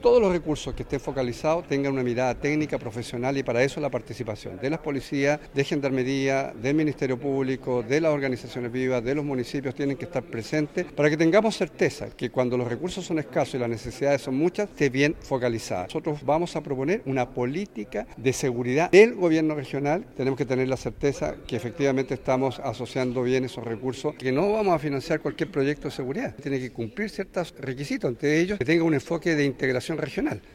El gobernador regional de Los Lagos, Alejandro Santana, indicó que no financiarán cualquier proyecto de seguridad, sino aquellos que cumplen con los requisitos como contar con un enfoque de integración regional.